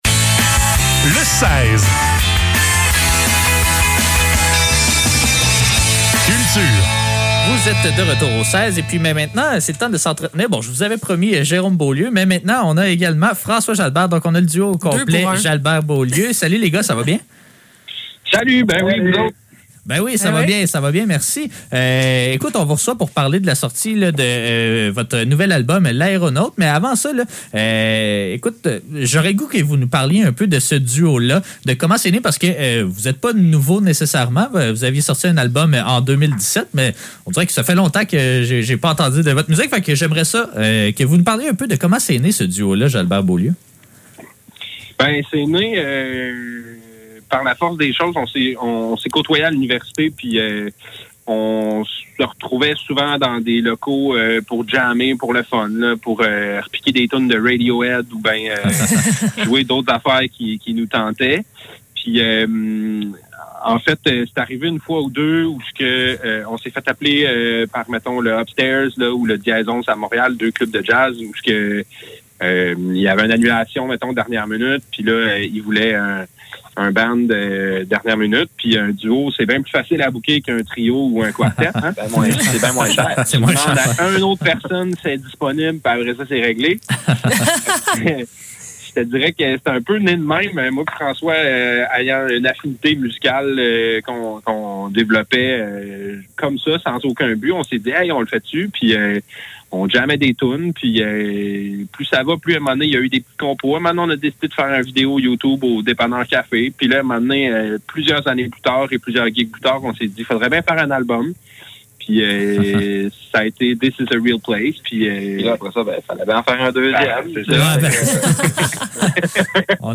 Les entrevues de CFAK Le seize - Entrevue